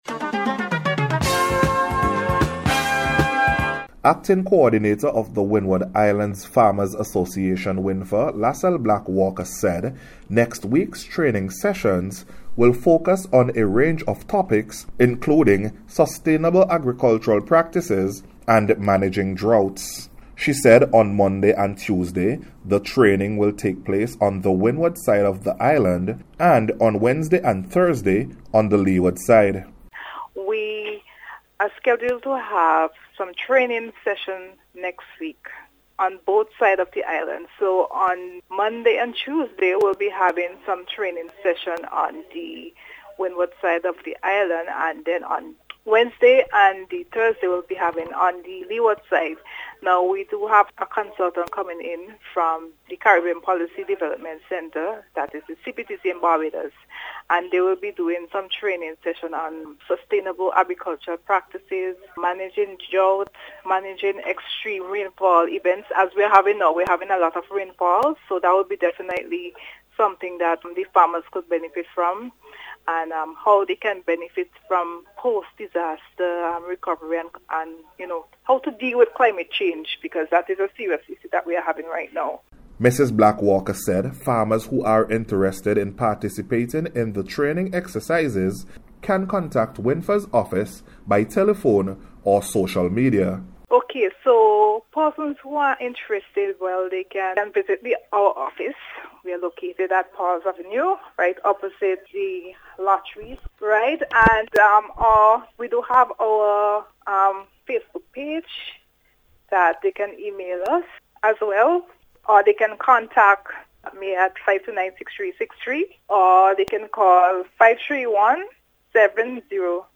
WINFA-FARMERS-TRAINING-REPORT.mp3